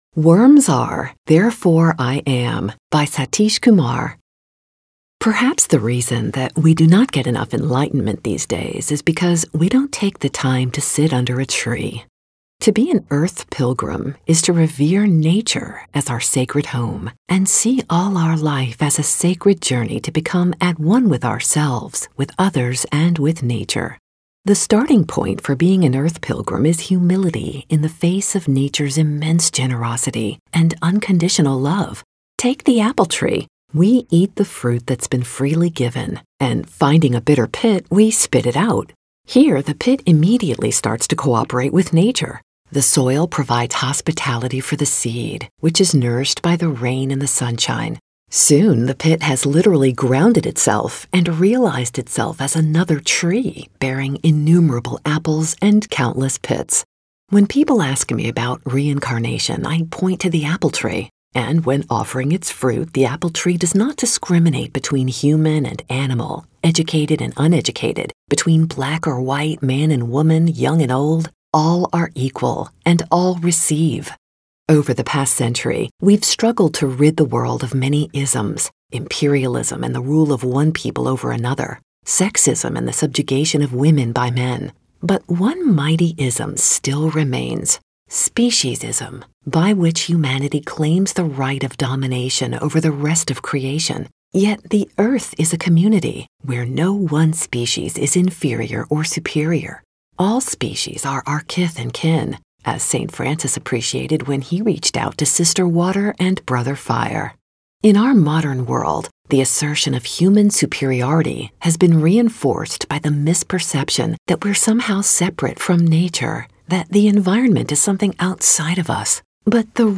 AUDIO RECORDING Couldn't load media player! Reading